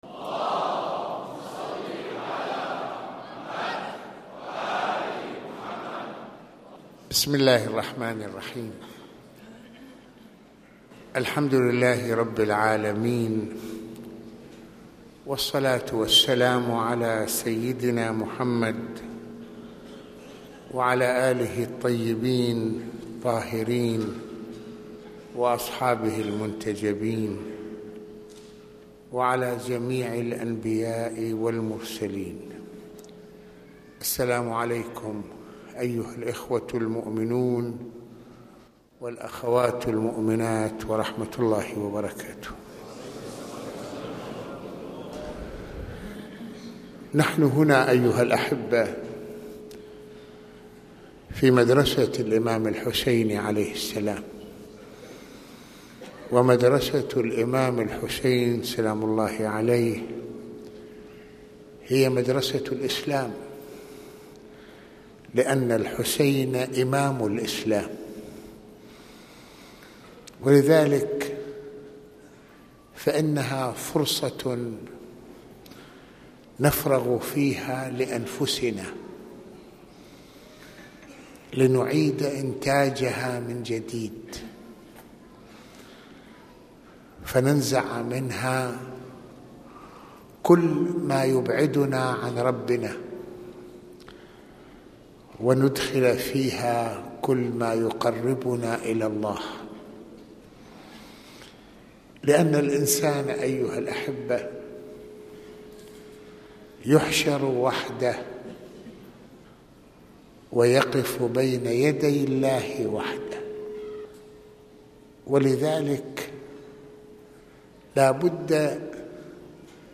المناسبة : احياء الليلة الثانية من عاشوراء المكان : مسجد الامامين الحسنين